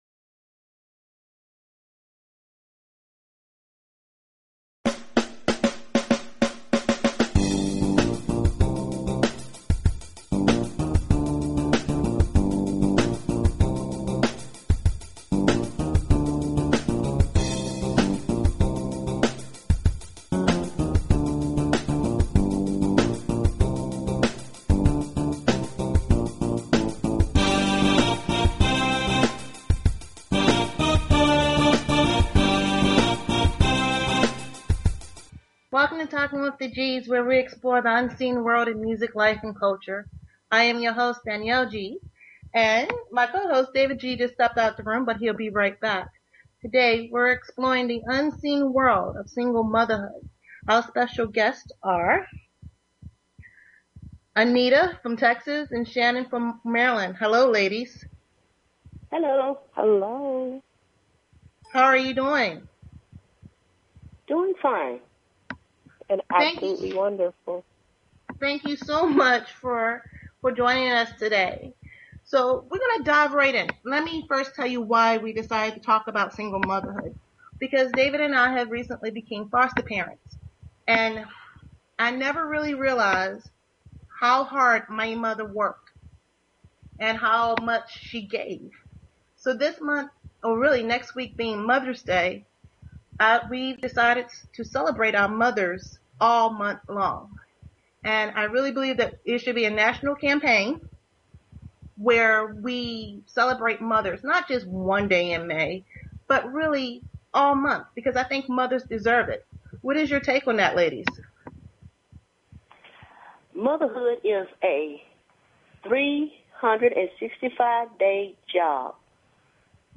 Talk Show Episode, Audio Podcast, Talking_with_the_Gs and Courtesy of BBS Radio on , show guests , about , categorized as